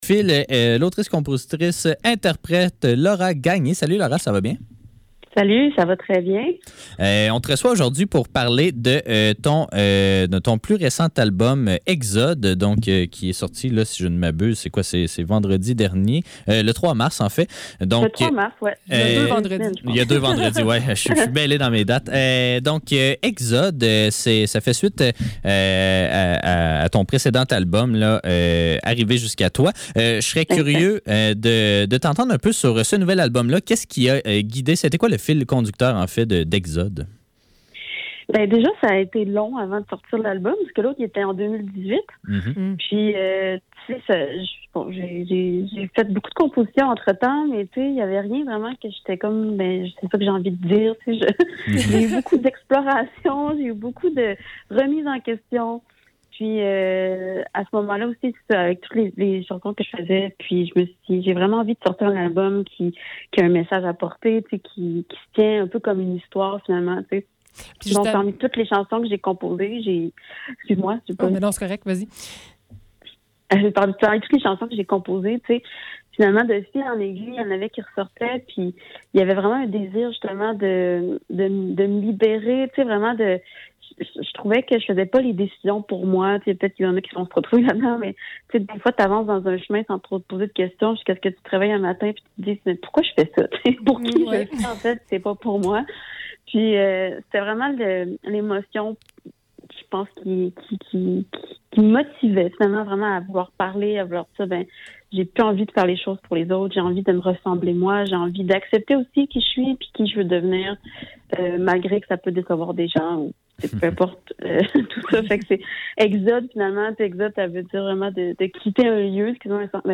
Le seize - Entrevue